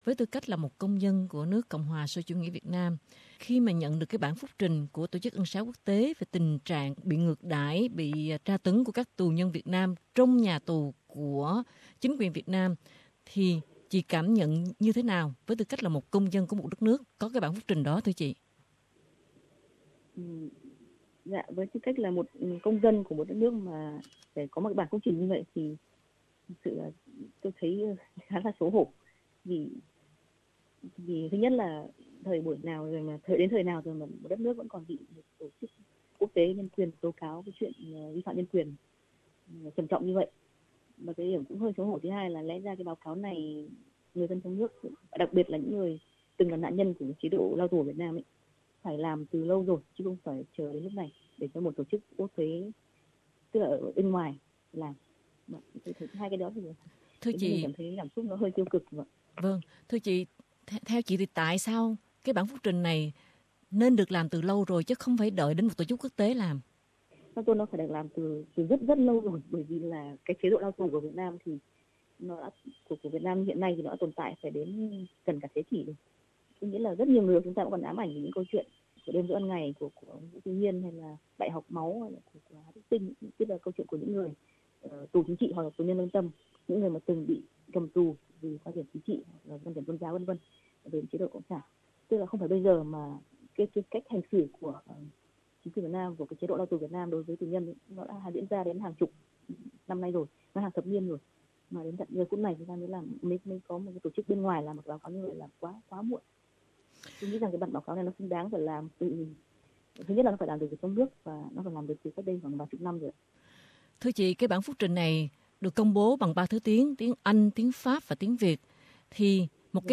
cuộc phỏng vấn